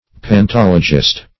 pantologist - definition of pantologist - synonyms, pronunciation, spelling from Free Dictionary
Pantologist \Pan*tol"o*gist\, n. One versed in pantology; a writer of pantology.